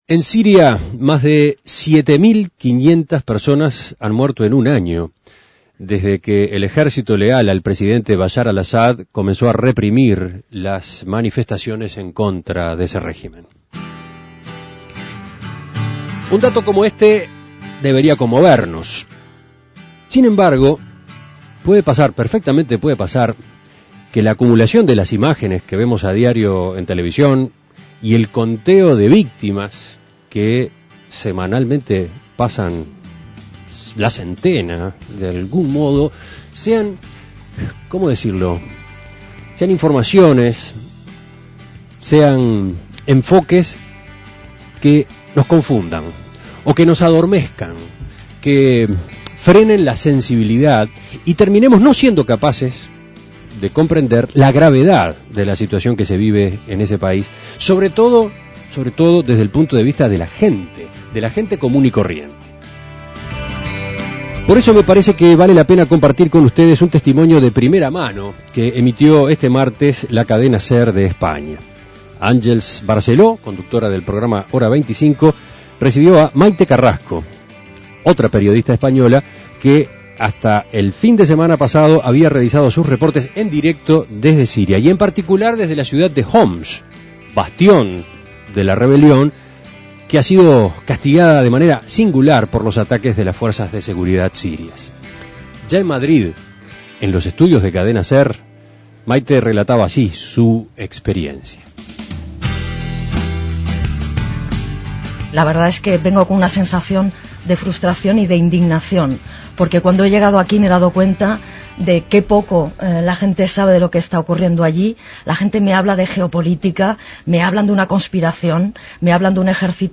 Reportaje de la Cadena Ser, de España, presentado por En Perspectiva.